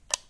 switch37.wav